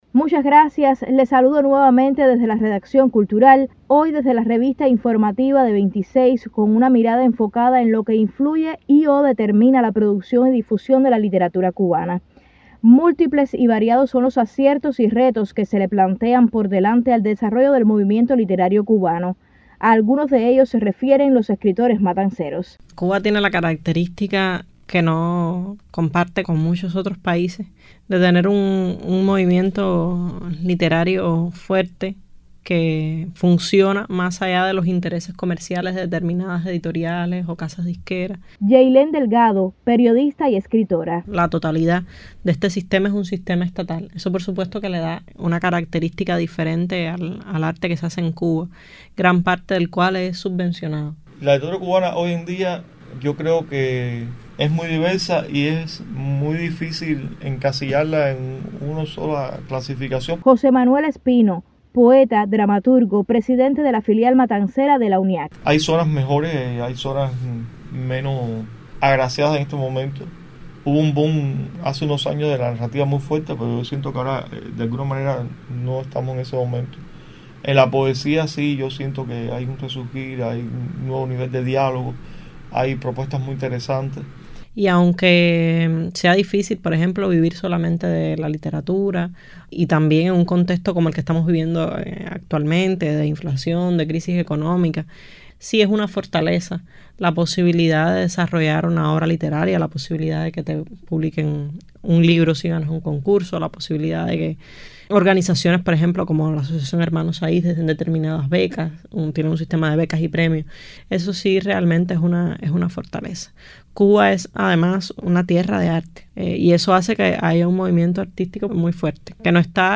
Estas son las opiniones de algunos escritores matanceros.